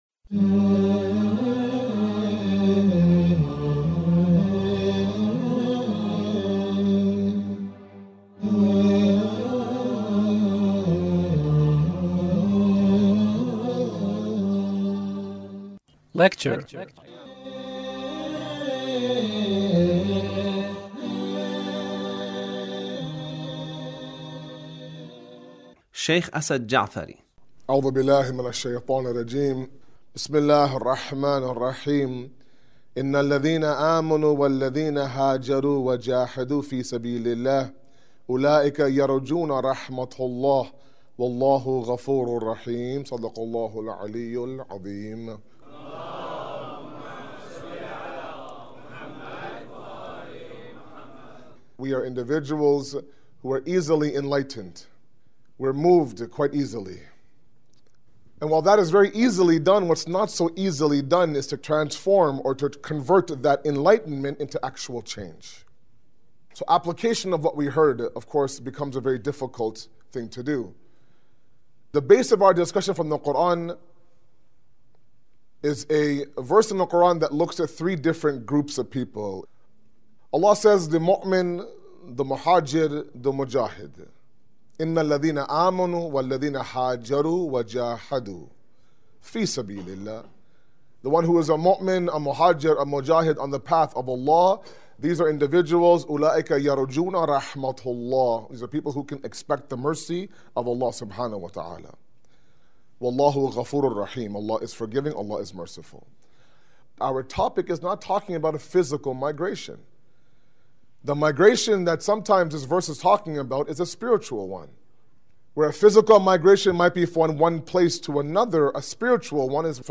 Lecture (5)